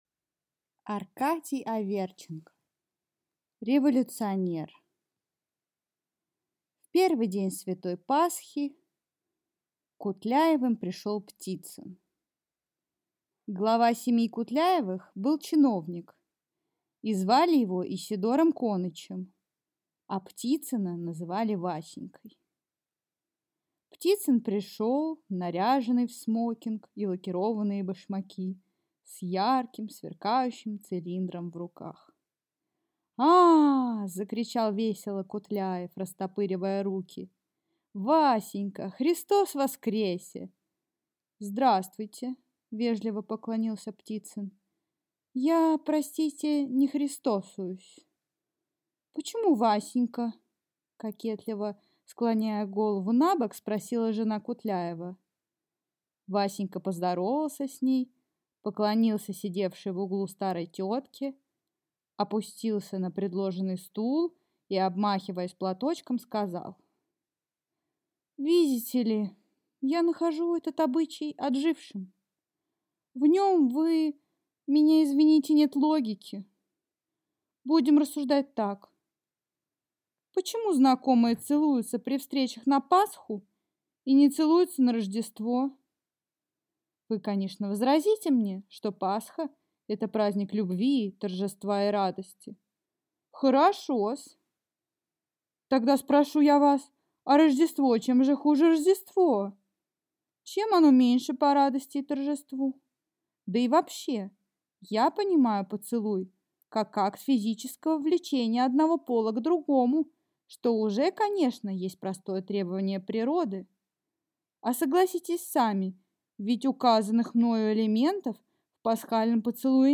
Аудиокнига Революционер | Библиотека аудиокниг